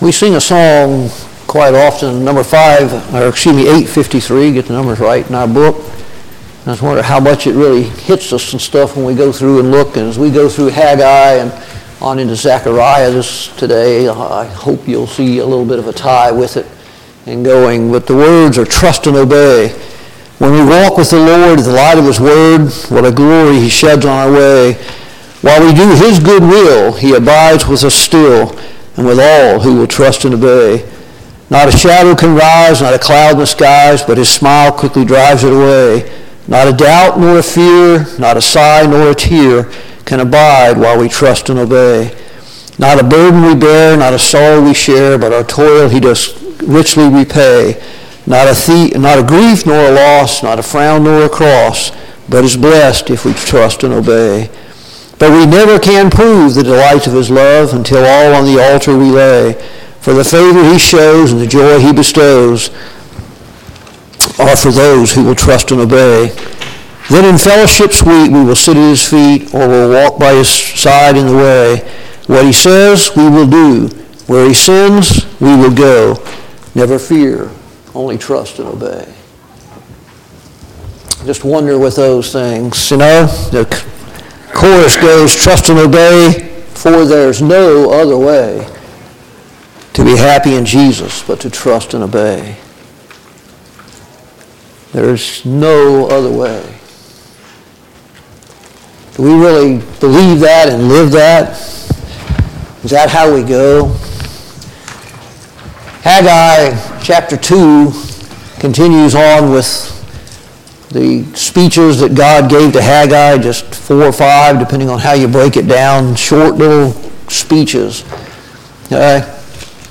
Study on the Minor Prophets Service Type: Sunday Morning Bible Class « 22.